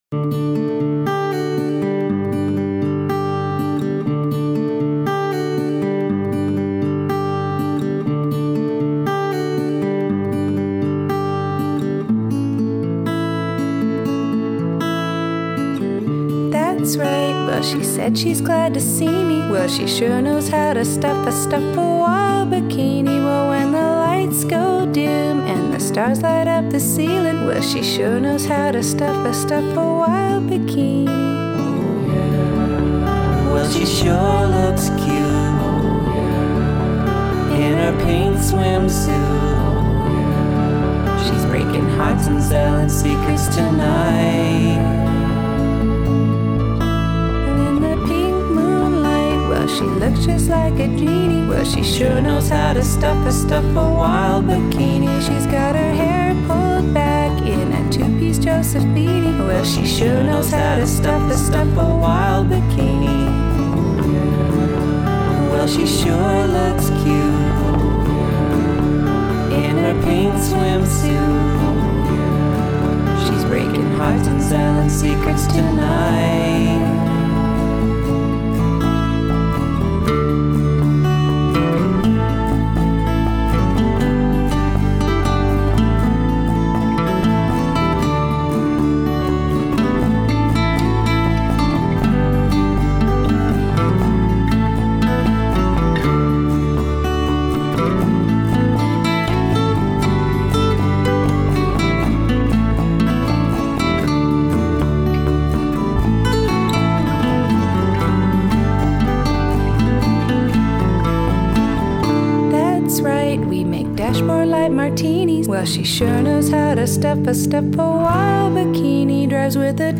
anti-folk collective
a patriotic version